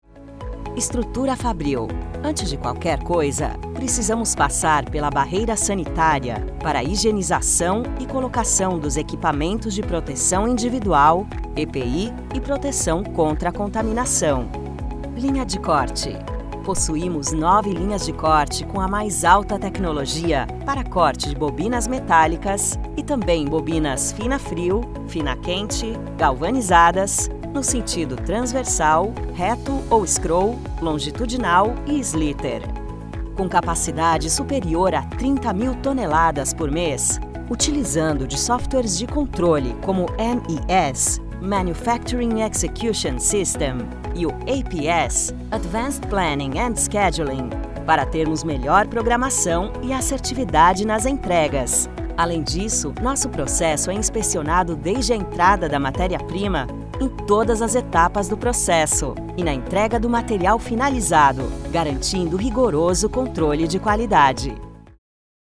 Natürlich, Vielseitig, Warm
Erklärvideo